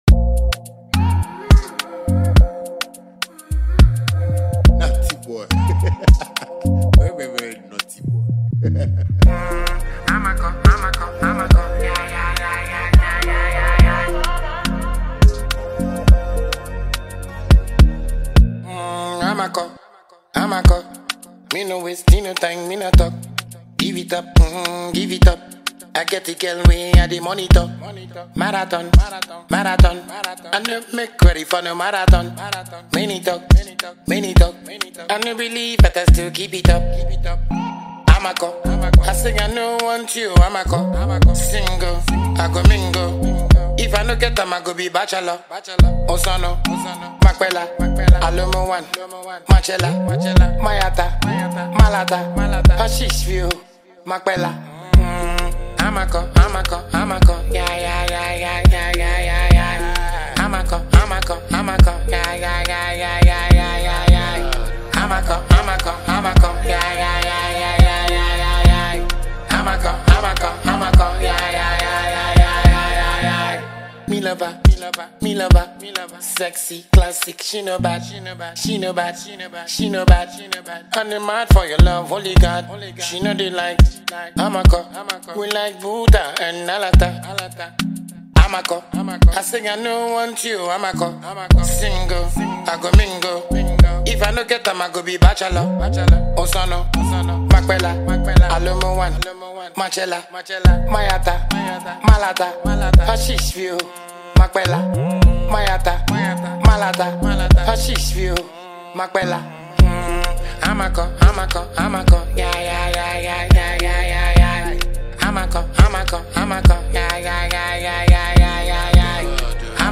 Ghanaian dancehall sensation